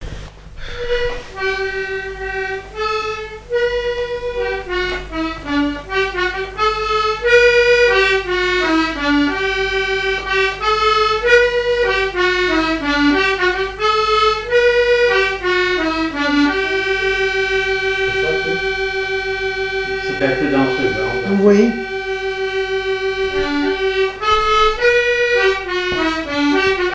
marche nuptiale
accordéon diatonique
Pièce musicale inédite